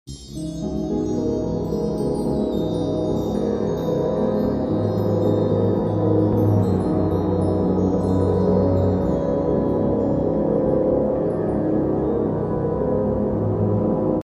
ASMR Fantasy Bite